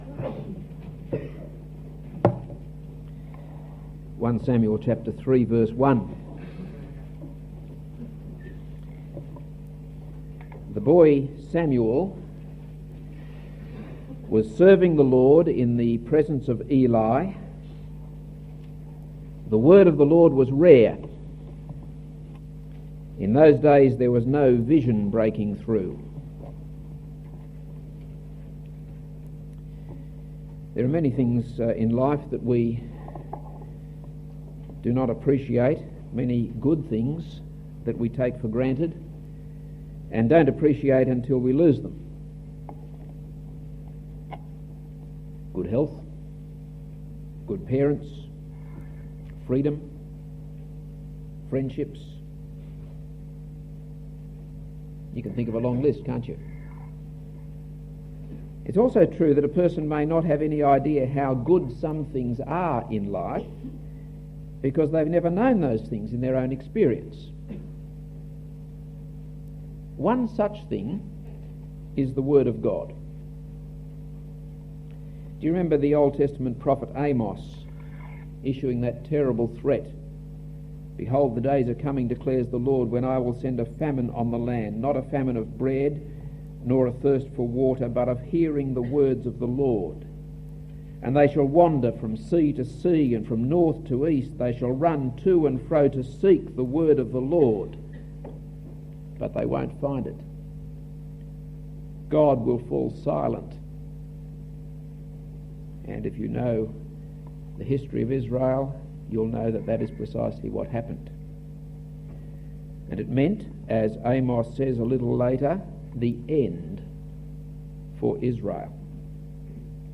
This is a sermon on 1 Samuel 3:1-4:1.